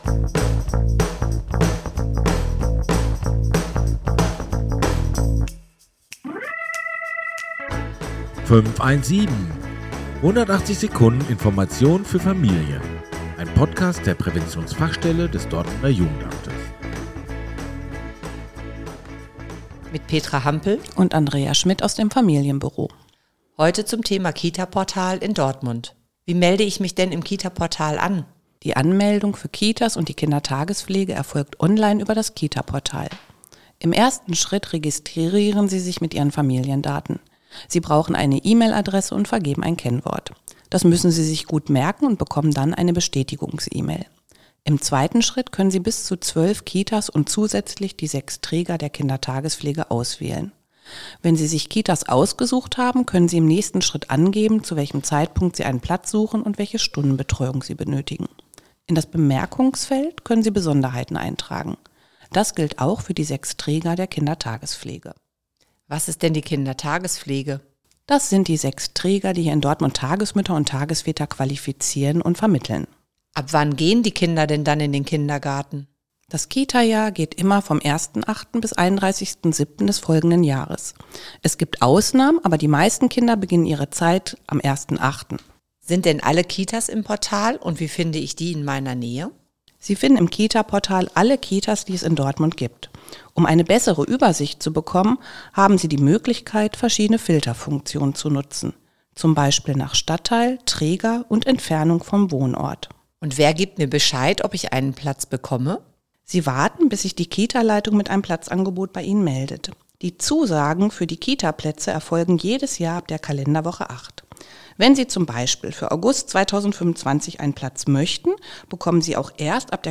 In rund 180 Sekunden sind zu Themen wie der Willkommensbesuch, die Arbeit einer Hebamme oder Mehrsprachigkeit interessante Menschen zu Gast, die im Gespräch mit Fachkräften der Präventionsfachstelle Einblicke in ihre Arbeit bzw. ihre Themen geben.